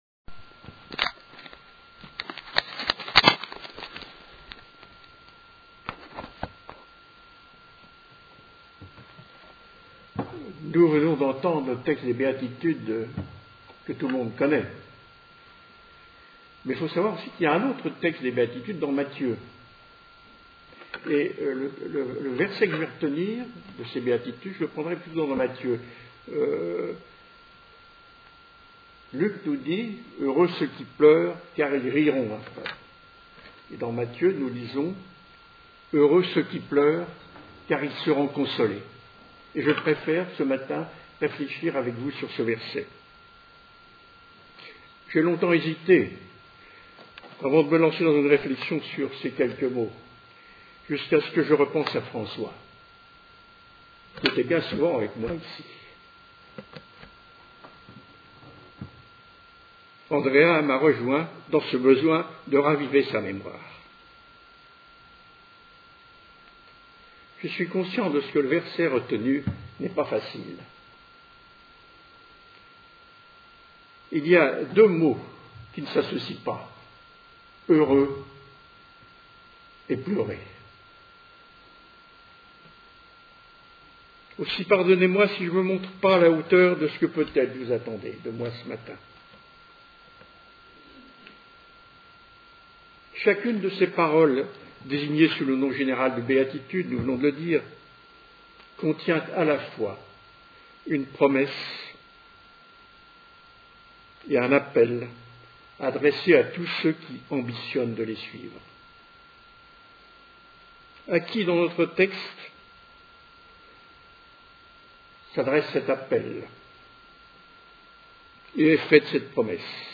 Podcasts prédications
Ces prédications ont été données le 24 janvier 10, dans le cadre d'un culte en commun de l'Alliance Protestante et Evangélique d’Annecy. Texte biblique : 1 Corinthiens 12.